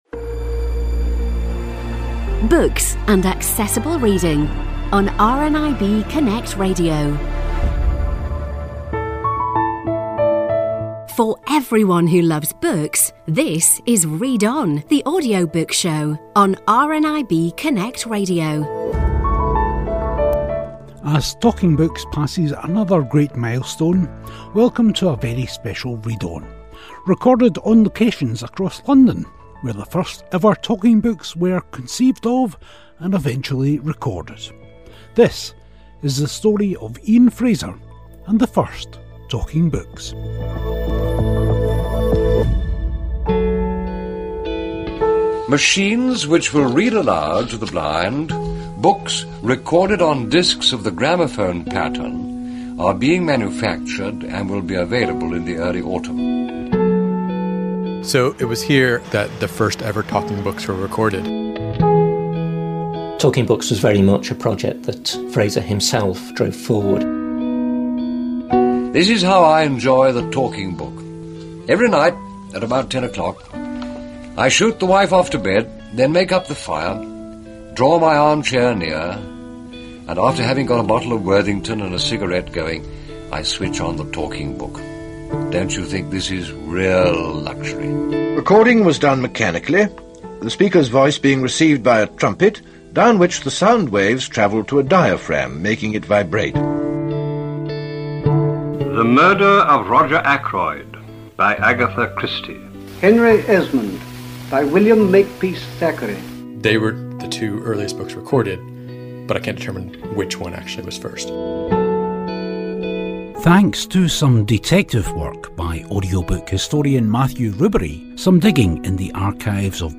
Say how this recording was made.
A weekly show all about audiobooks recorded at the RNIB Talking Book studios. We talk to your favourite authors and narrators, along with reviews and news about new audiobooks.